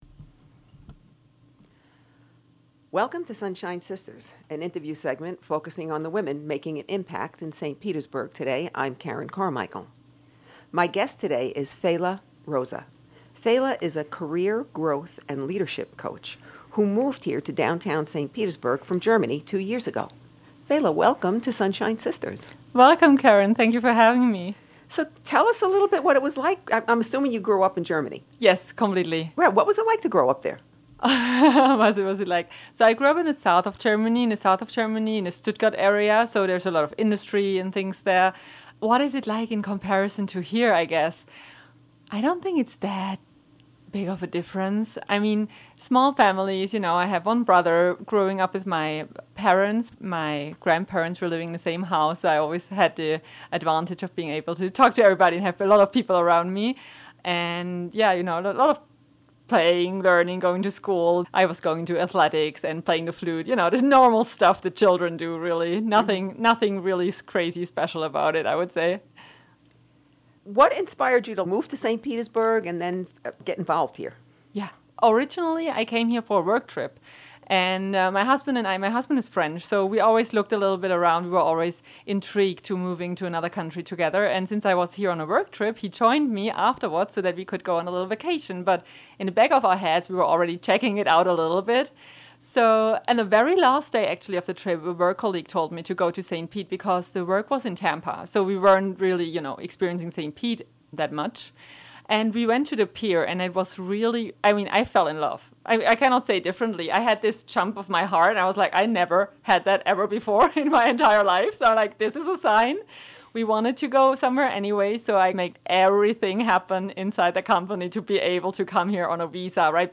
Books and Movies referenced in this interview: